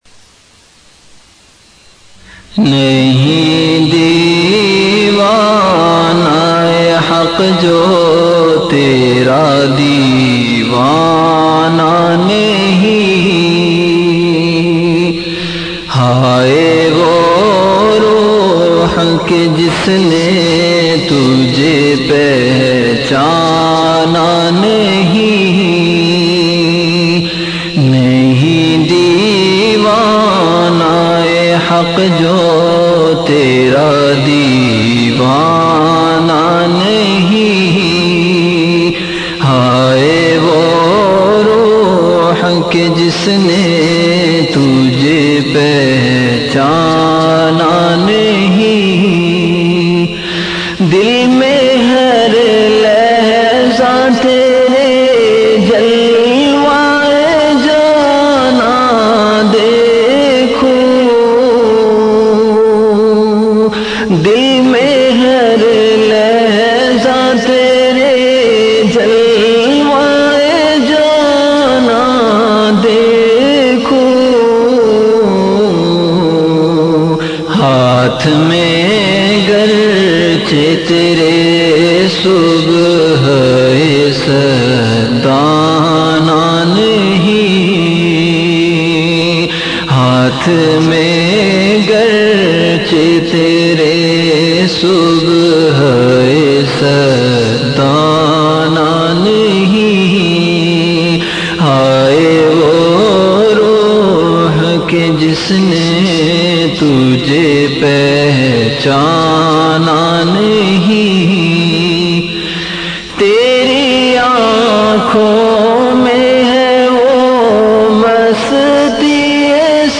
Delivered at Home.
Download MP3 Share on WhatsApp Audio Details Category Ashaar Duration 10 min Date Islamic Date Venue Home Event / Time After Isha Prayer Listeners 1,877 File Size 4 MB Have a question or thought about this bayan?